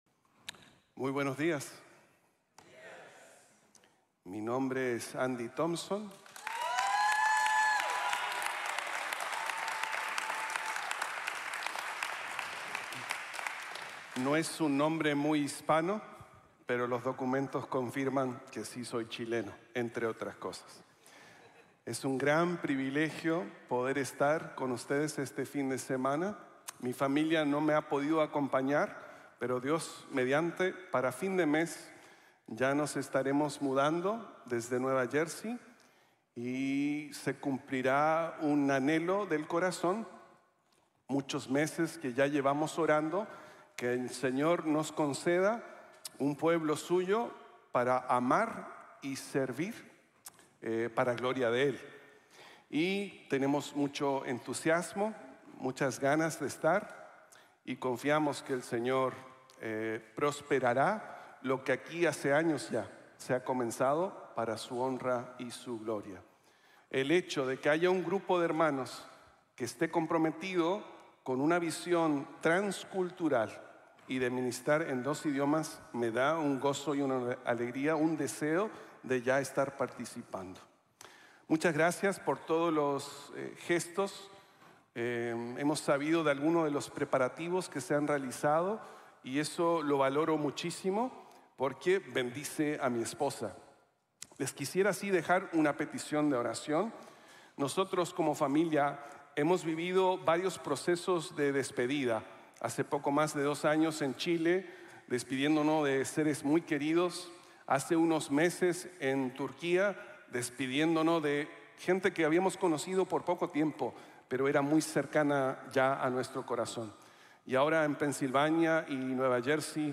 Ni se les pasó por la cabeza | Sermon | Grace Bible Church